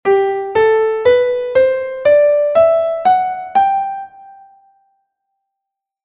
Escalas maiores